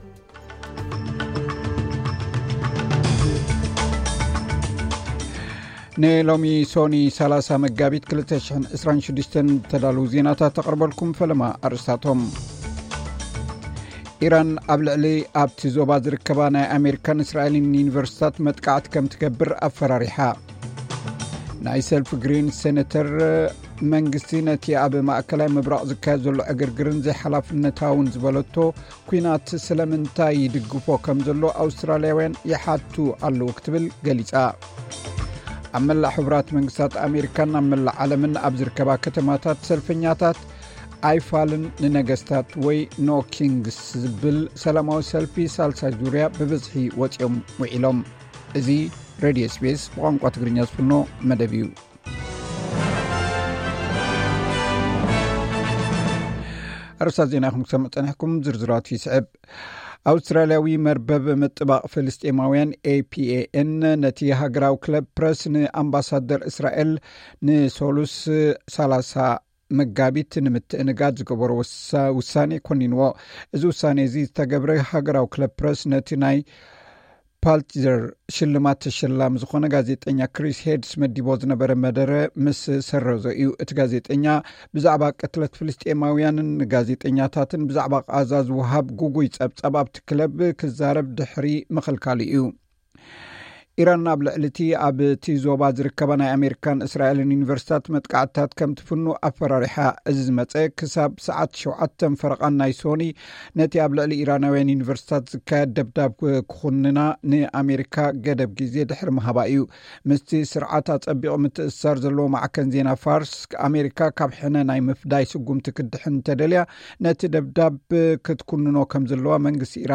ዕለታዊ ዜና SBS ትግርኛ (30 መጋቢት 2026)